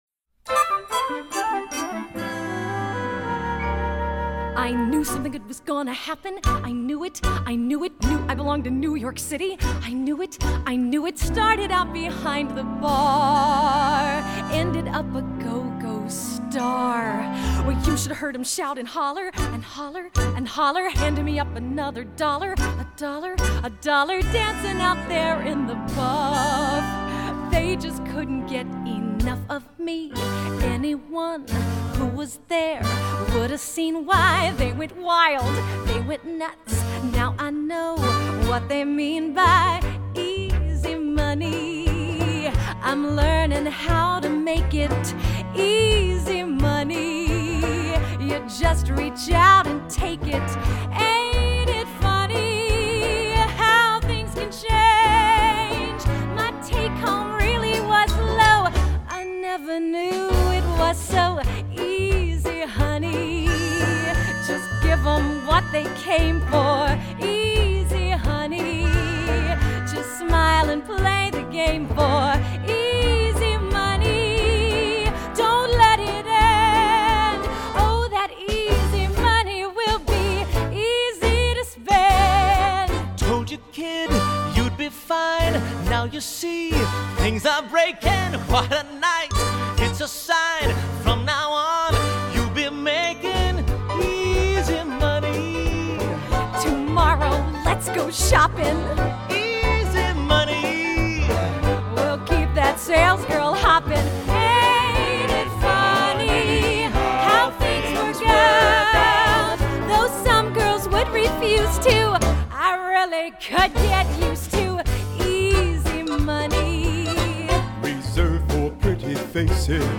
1996  Genre: Musical   Artist